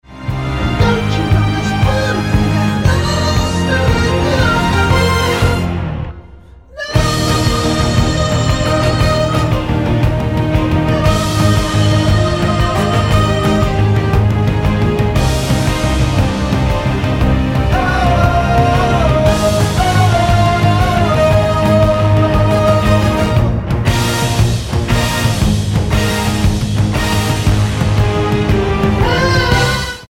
Tonart:Eb mit Chor
Die besten Playbacks Instrumentals und Karaoke Versionen .